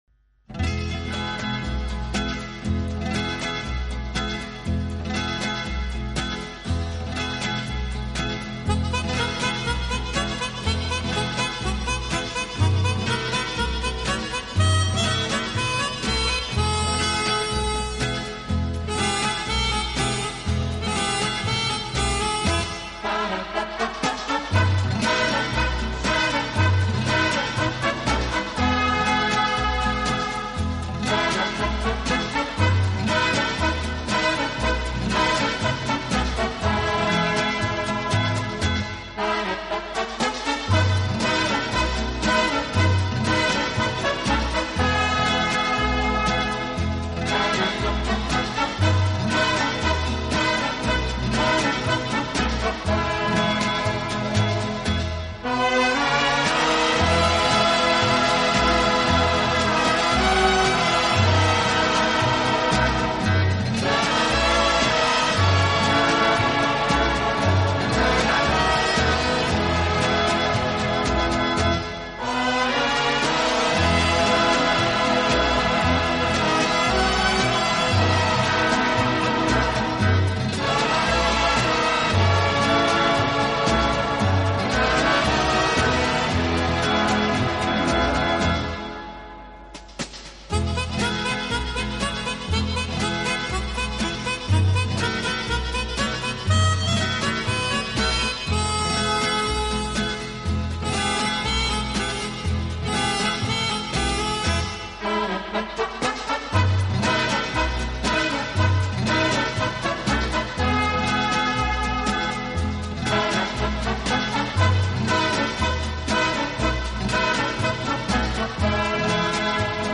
【轻音乐】